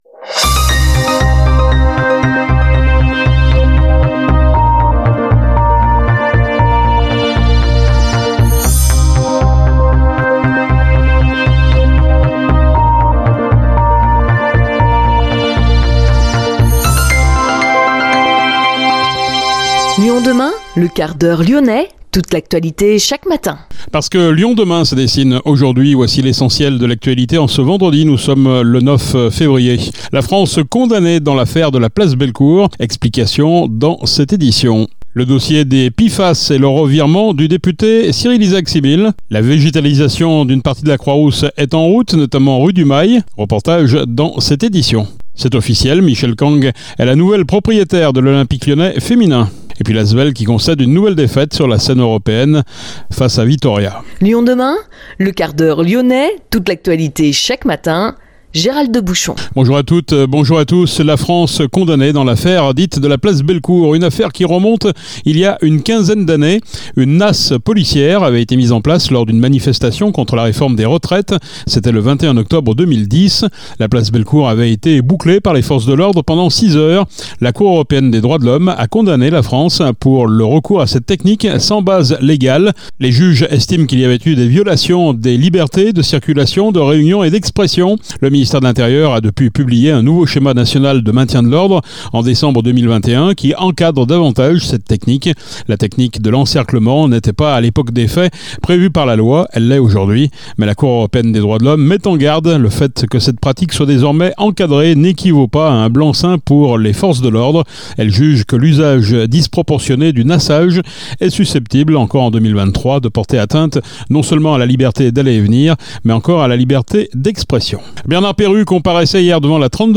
Grégory Doucet , maire de Lyon, Rémi Zinck , maire du 4,